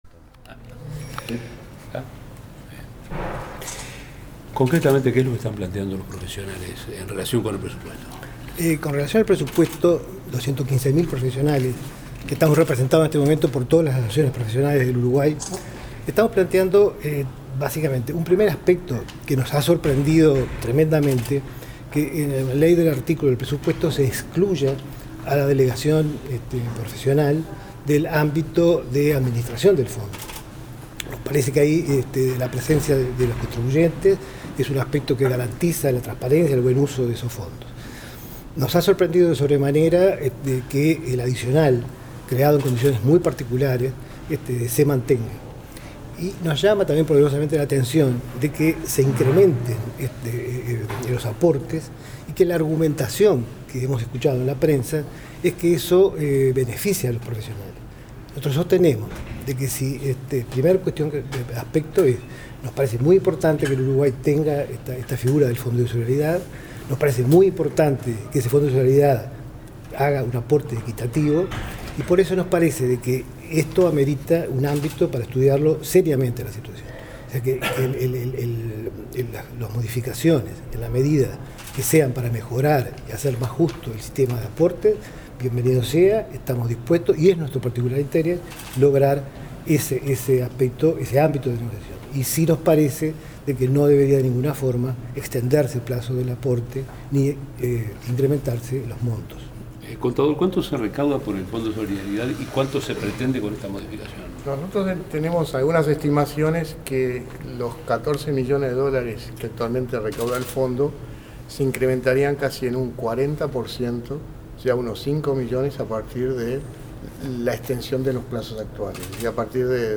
CONFERENCIA DE PRENSA
En el día de ayer, se realizó en el Edificio Anexo del Palacio Legislativo, una conferencia de prensa donde la Agrupación Universitaria del Uruguay (AUDU) y las Asociaciones de Profesionales Independientes (Intergremial) brindaron su parecer respecto a las modificaciones en el Fondo de Solidaridad inclujidas en el Proyecto de Ley del Presupuesto enviado por el Poder Ejecutivo.